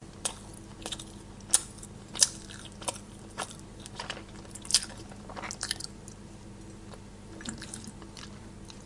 先进的多媒体技术 " 咀嚼污渍
描述：涂抹嚼着一些生菜的兔子
标签： 兔子 咀嚼 生菜 格格 蒙克 咀嚼 紧缩 捣鼓
声道立体声